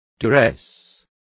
Complete with pronunciation of the translation of duress.